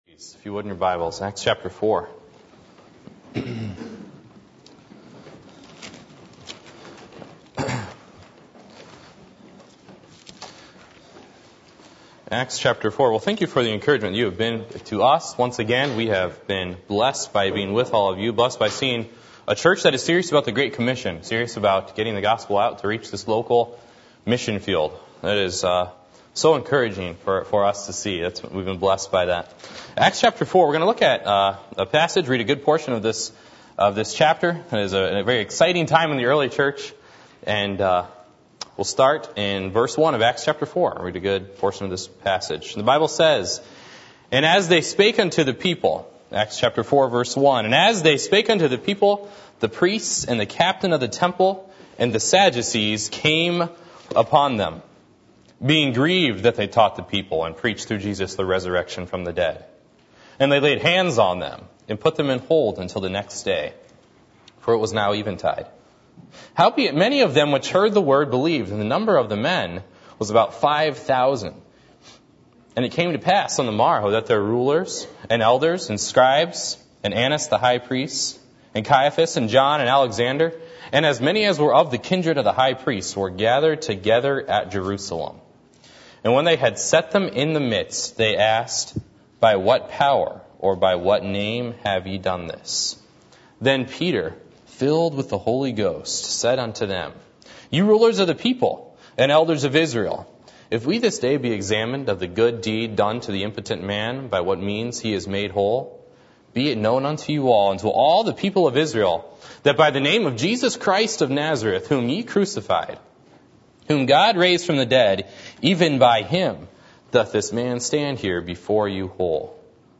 Passage: Acts 4:1-21 Service Type: Sunday Evening